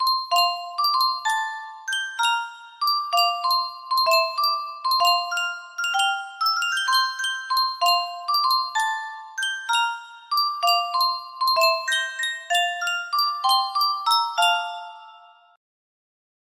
Sankyo Music Box - The Rose of Tralee XTA music box melody
Full range 60